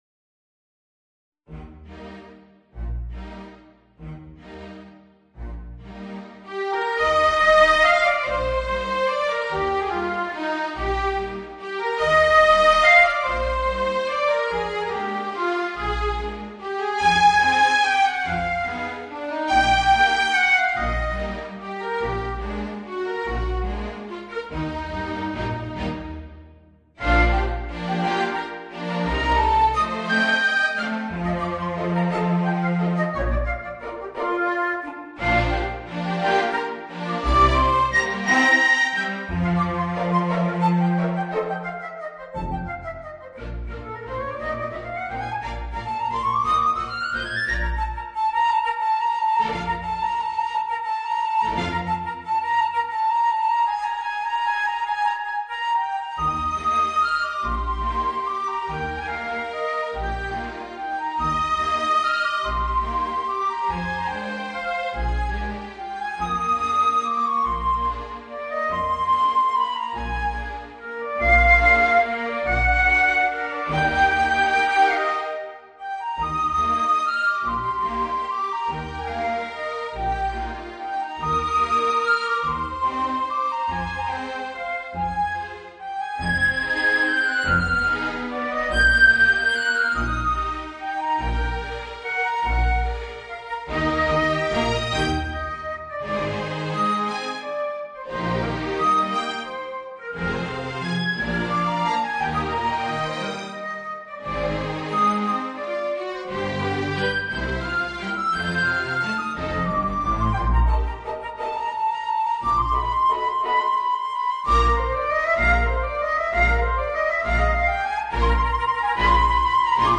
Voicing: Clarinet and String Quintet